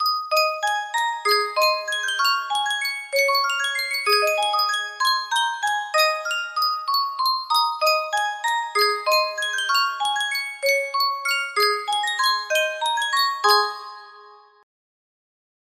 Sankyo Music Box - Home on the Range M
Full range 60